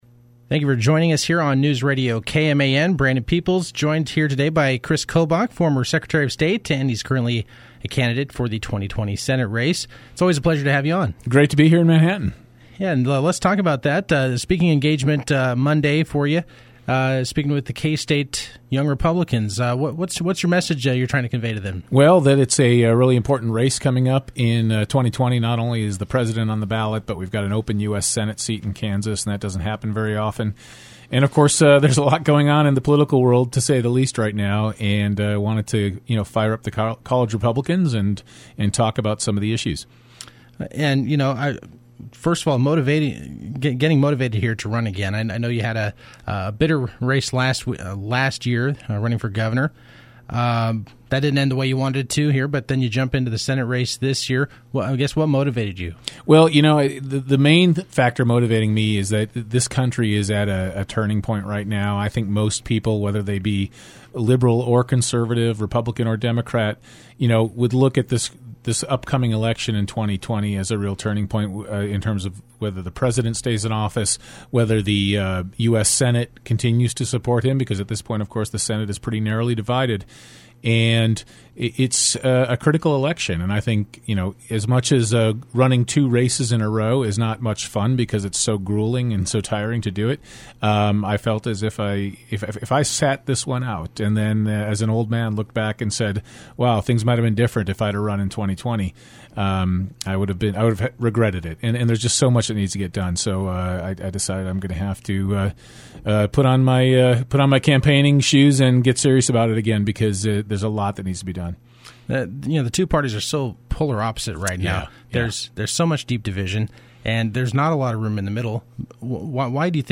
Kobach, one of several GOP candidates declared in the 2020 race, spoke with News Radio KMAN prior to his talk at K-State Monday.
Kris-Kobach-on-KMAN-Oct-7-2019.mp3